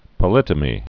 (pə-lĭtə-mē)